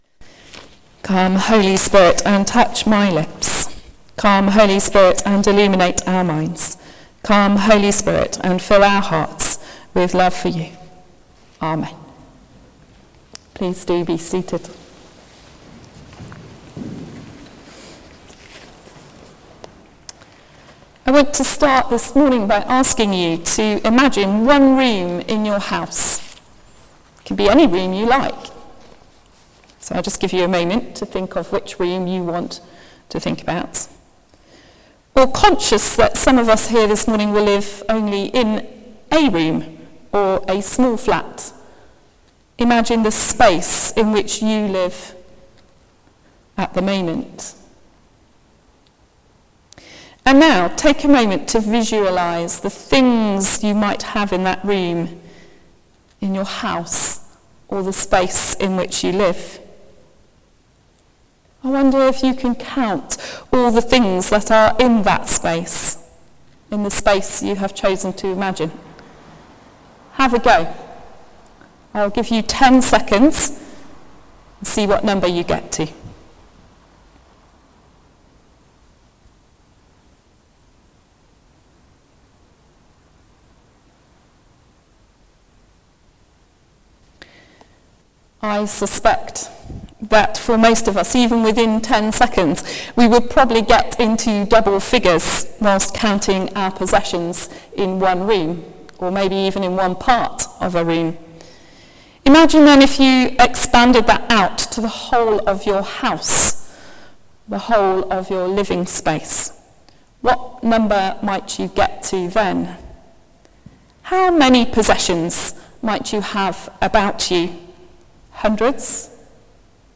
Sermon: Harvest | St Paul + St Stephen Gloucester